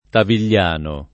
Tavigliano [ tavil’l’ # no ]